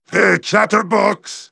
synthetic-wakewords
ovos-tts-plugin-deepponies_Rainbow Dash_en.wav